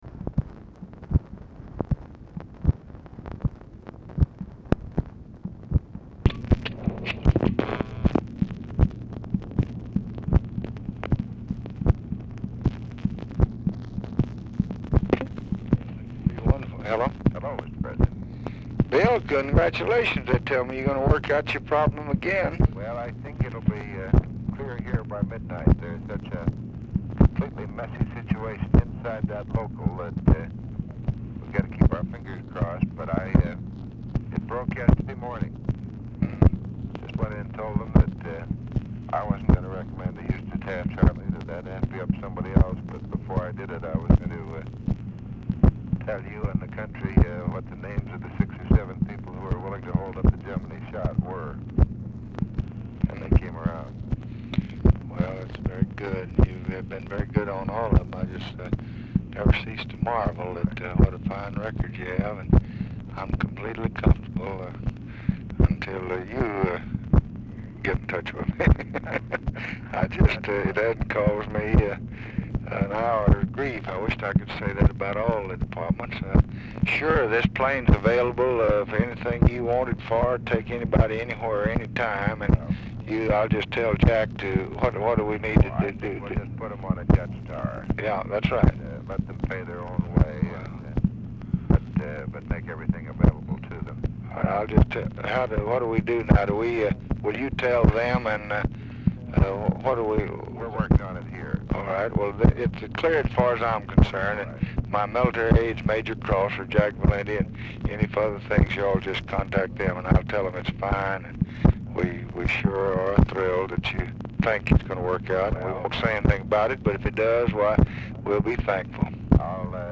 Telephone conversation # 9189, sound recording, LBJ and WILLARD WIRTZ
Format Dictation belt
Location Of Speaker 1 LBJ Ranch, near Stonewall, Texas